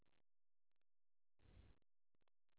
kyo 0141 (Monaural AU Sound Data)